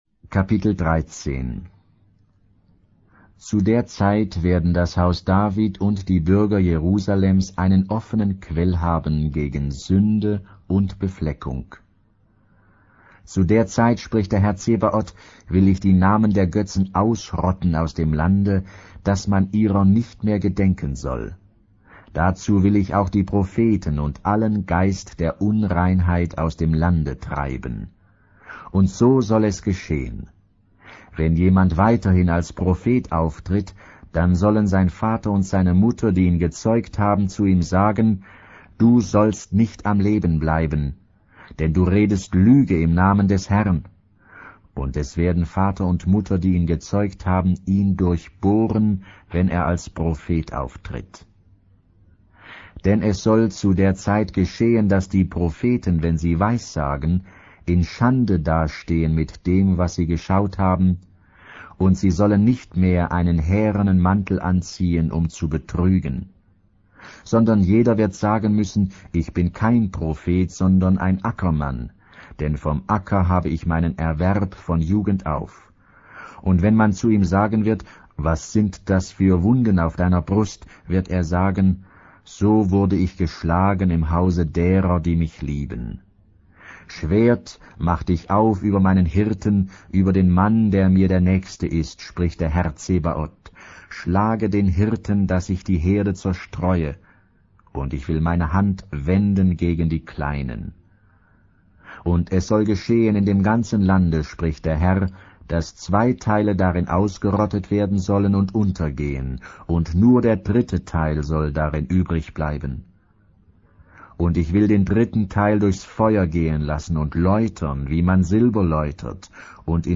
Audio Luther Bibel Sacharja